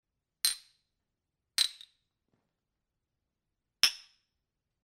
Звуки чоканья бокалов
Звук чоканья рюмок